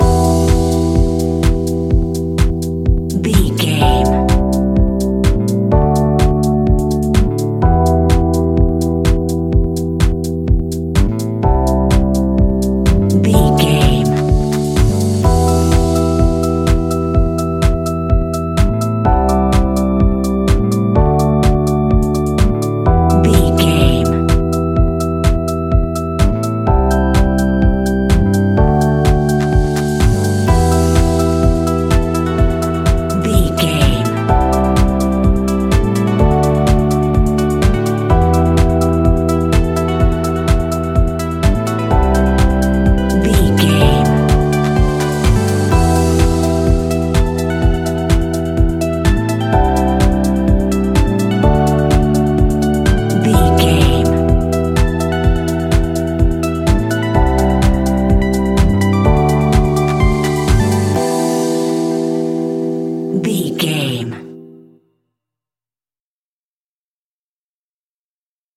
Aeolian/Minor
uplifting
energetic
bouncy
funky
bass guitar
synthesiser
electric piano
drum machine
funky house
deep house
nu disco
groovy
upbeat
instrumentals